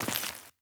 Glass_Grit_Mono_05.wav